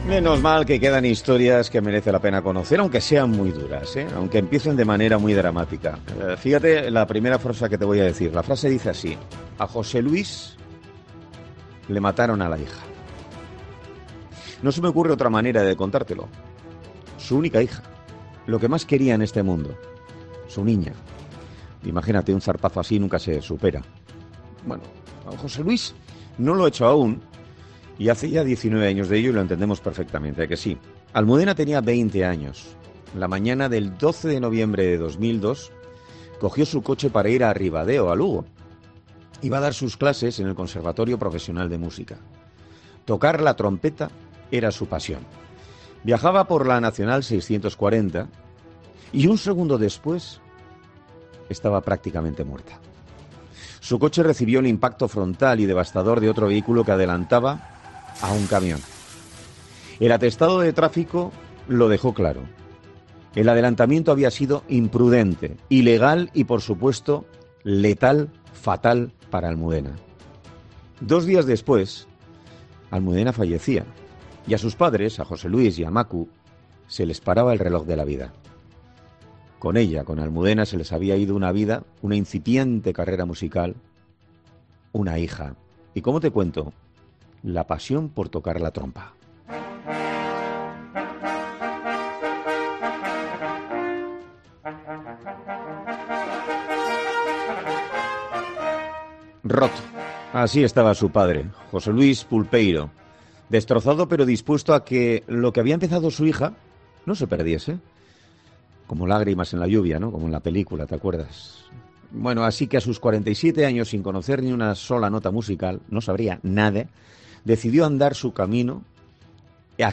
dice con la voz entrecortada.